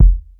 07_Kick_02_SP.wav